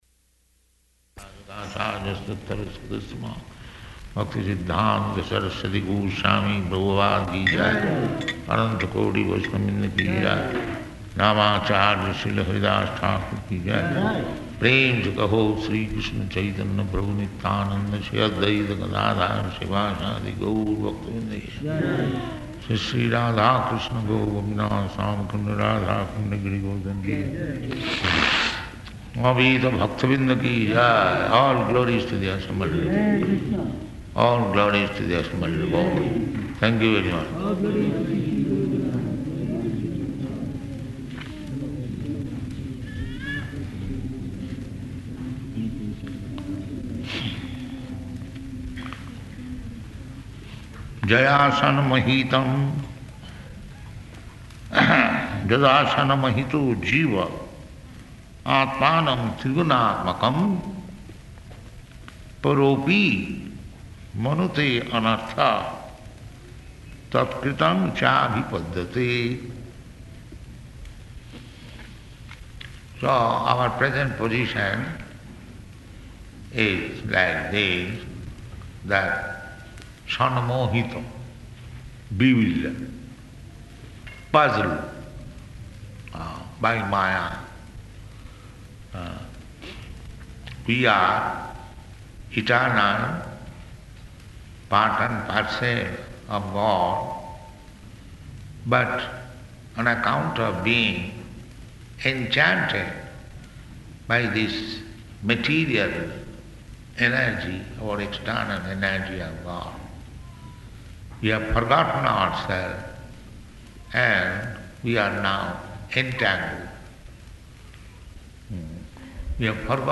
Śrīmad-Bhāgavatam 1.7.5-6 --:-- --:-- Type: Srimad-Bhagavatam Dated: October 15th 1975 Location: Johannesburg Audio file: 751015SB.JOH.mp3 Prabhupāda: [ prema-dhvani ] Thank you very much. [devotees offer obeisances] [00:50] yayā sammohito jīva ātmānaṁ tri-guṇātmakam paro 'pi manute 'narthaṁ tat-kṛtaṁ cābhipadyate [ SB 1.7.5 ] So our present position is like this, that sammohita, bewildered, puzzled by māyā.